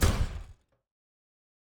pgs/Assets/Audio/Sci-Fi Sounds/MISC/Footstep Robot Large 2_04.wav at 7452e70b8c5ad2f7daae623e1a952eb18c9caab4
Footstep Robot Large 2_04.wav